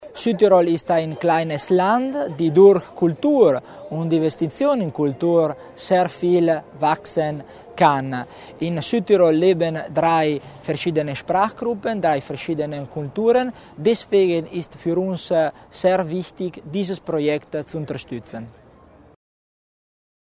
Landesrat Tommasini zur Bedeutung der Kandidatur